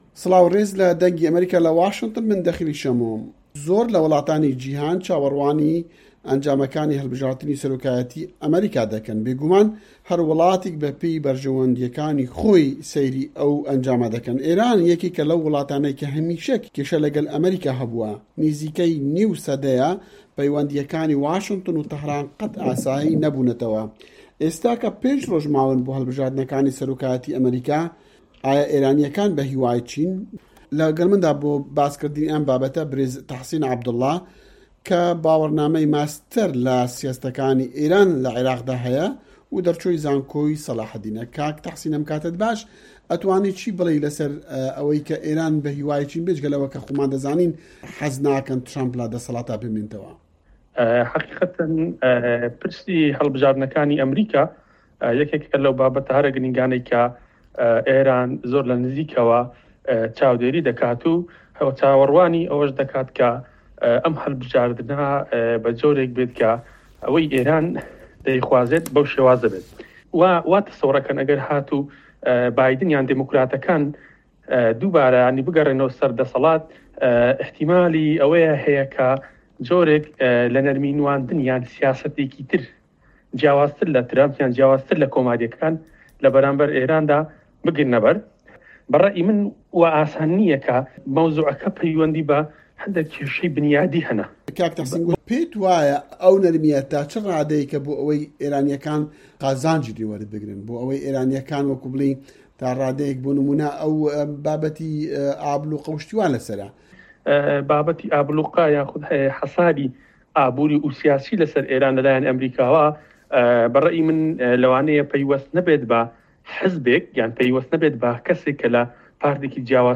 ئه‌مه‌ریکا - گفتوگۆکان